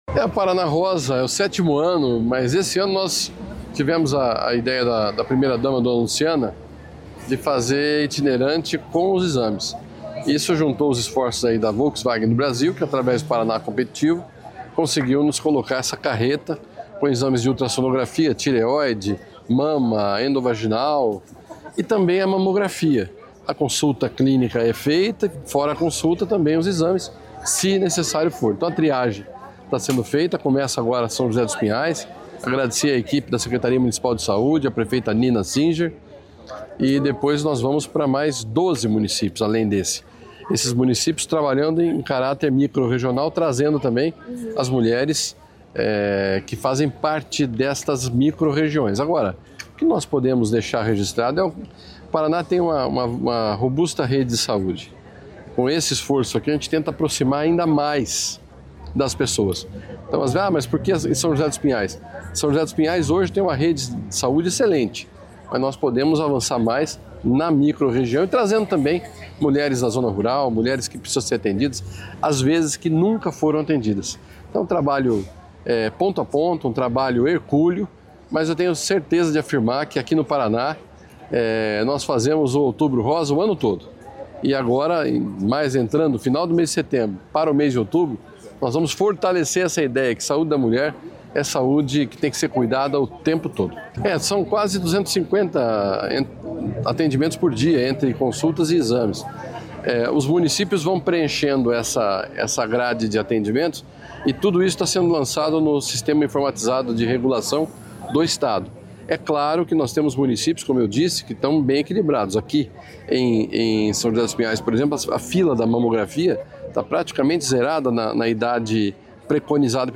Sonora do secretário da Saúde, Beto Preto, sobre a Carreta Saúde da Mulher